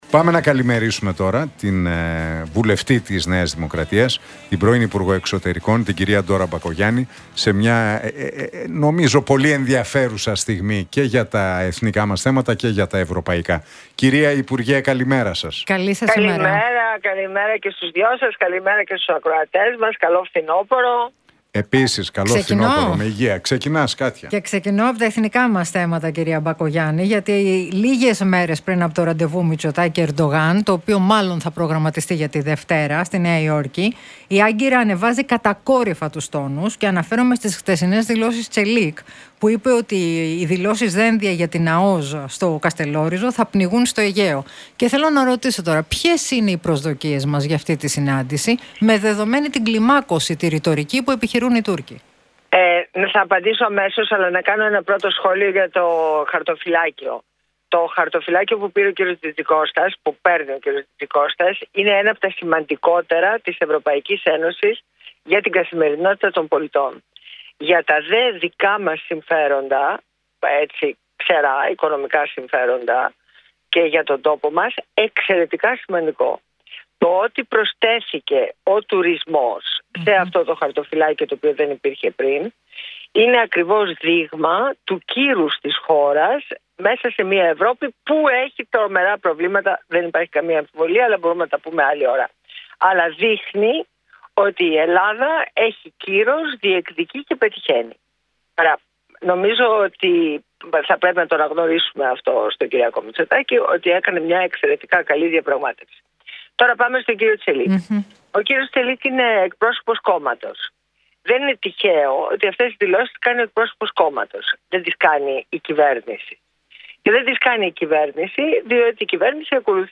Μπακογιάννη στον Realfm 97,8: Δεν είμαστε αφελείς να πιστεύουμε ότι η Τουρκία εγκατέλειψε τις πάγιες θέσεις της